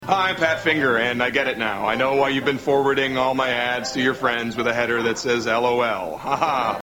Tags: Pat Finger is Running in Butts Pat Finger Running in Butts Pat Finger funny skit snl skit